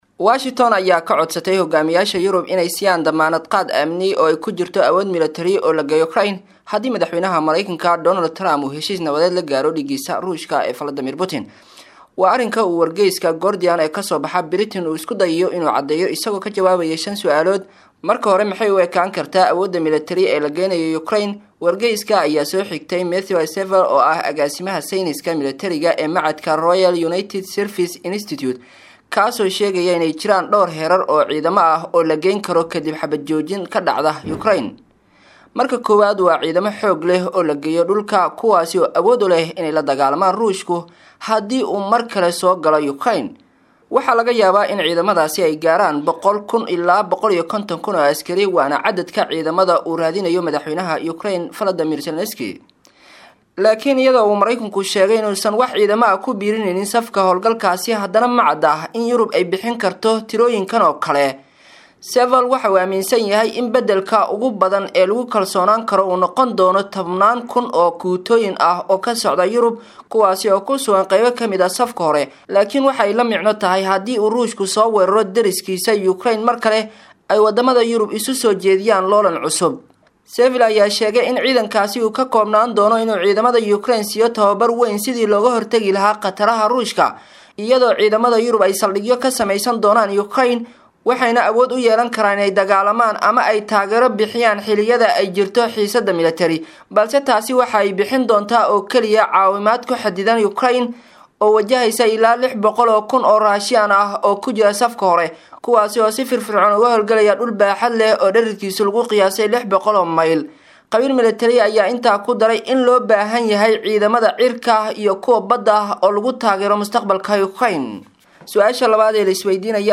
Warbixin_Maxay_Yihiin_Waxyaabaha_Isweydiinta_Mudan_si_aan_Ufahano.mp3